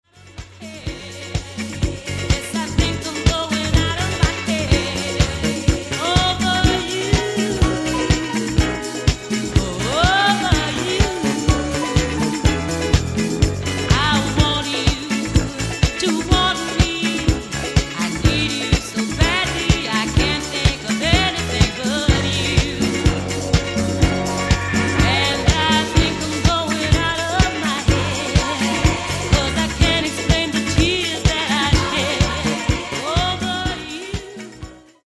Genere:   Disco | Soul |